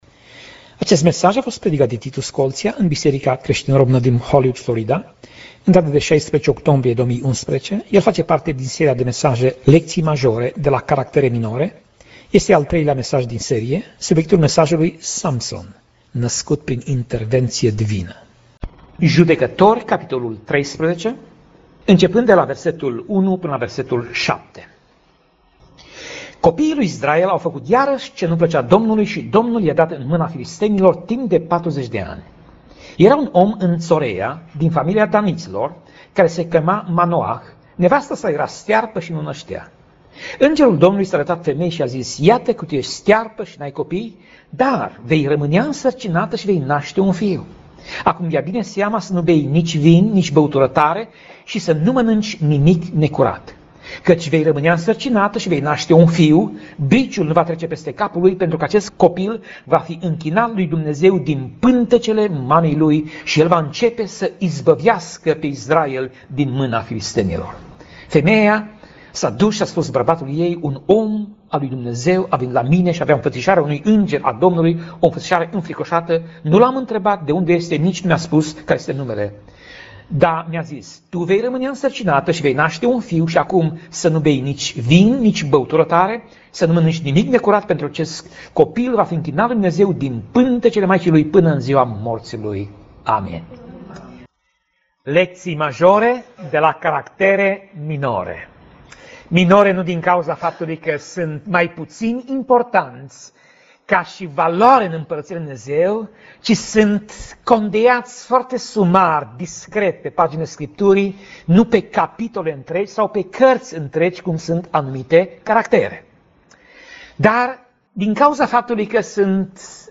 Pasaj Biblie: Judecatorii 13:1 - Judecatorii 13:7 Tip Mesaj: Predica